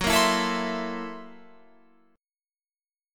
F#7#9b5 Chord
Listen to F#7#9b5 strummed